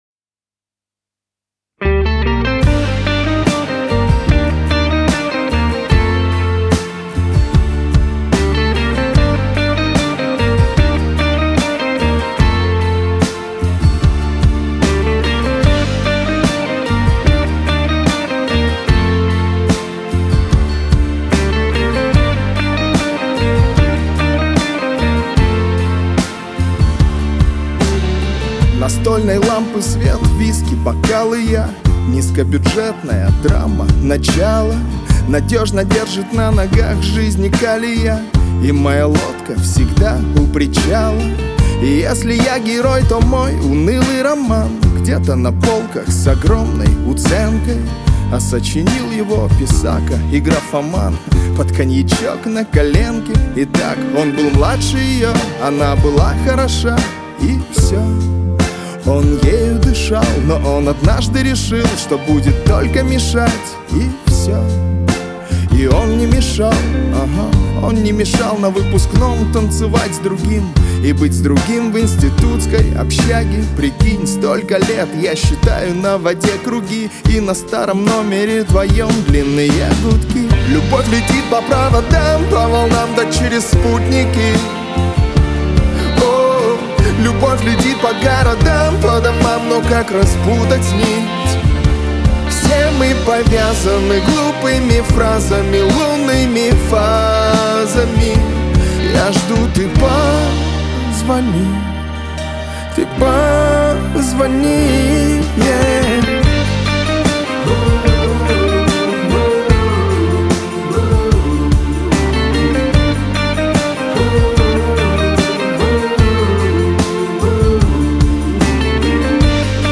Грустные